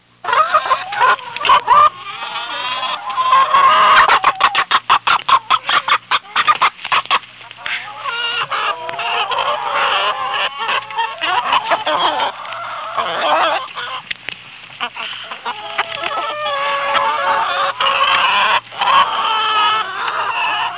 Chicken Sound Effect
Download a high-quality chicken sound effect.
chicken.mp3